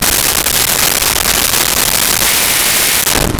Dryer With Buzzer
Dryer with Buzzer.wav